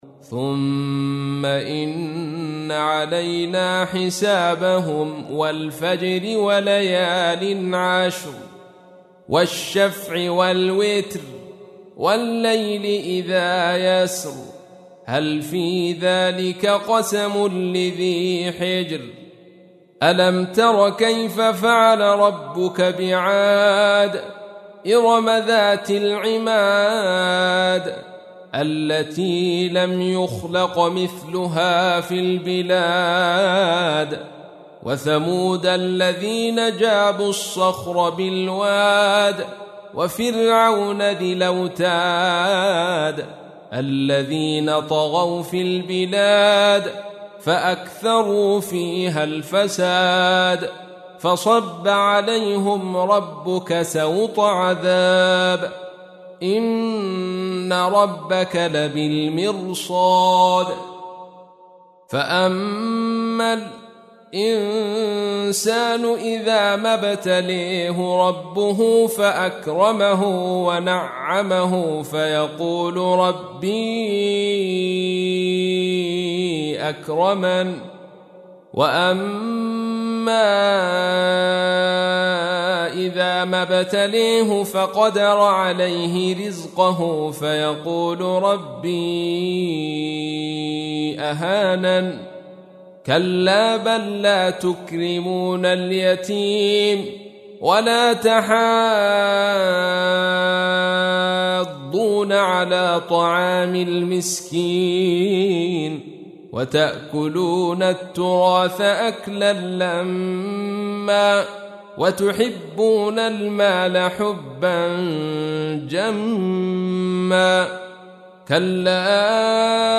تحميل : 89. سورة الفجر / القارئ عبد الرشيد صوفي / القرآن الكريم / موقع يا حسين